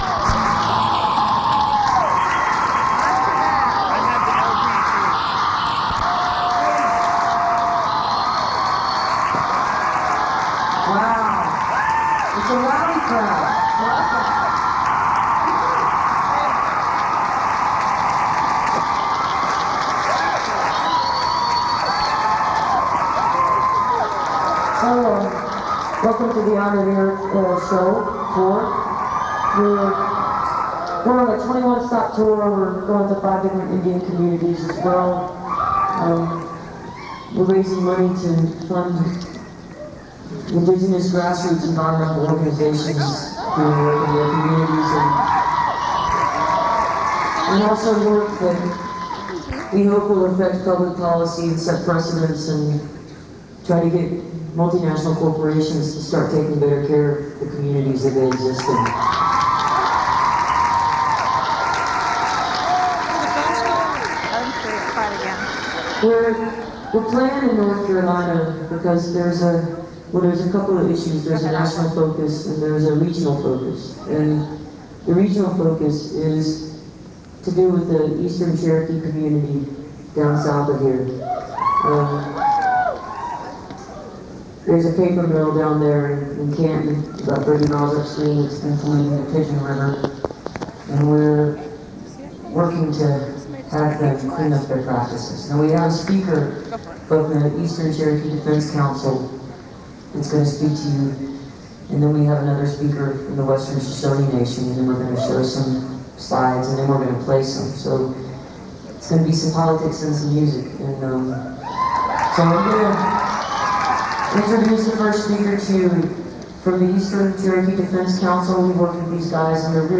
lifeblood: bootlegs: 1997-09-19: appalachian state student union - boone, north carolina
01. introduction by amy ray (2:12)